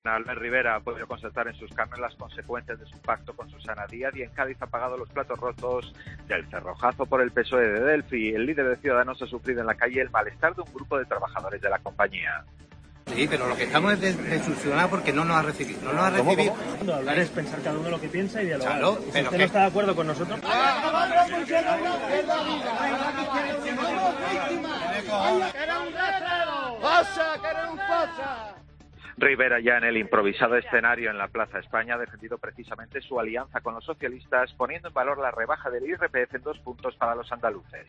Rivera abucheado en Cádiz por los trabajadores de Delphy.